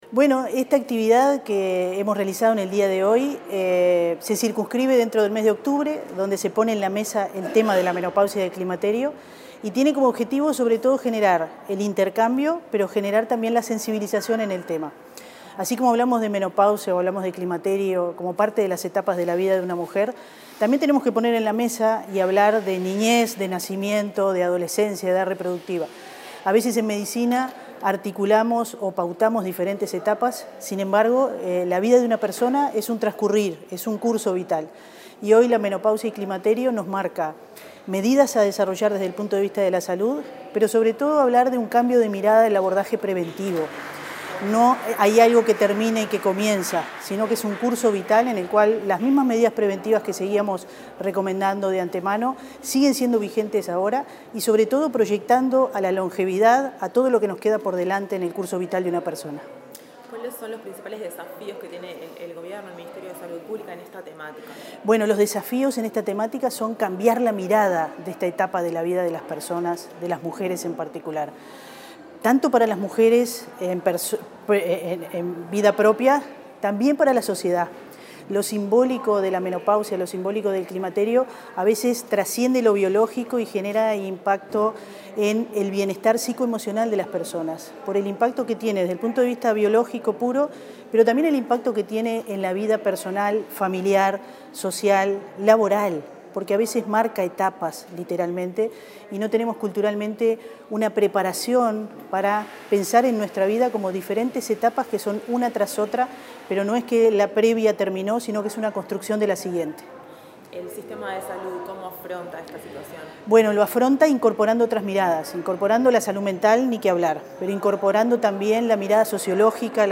Declaraciones de la directora general de Salud del MSP, Fernanda Nozar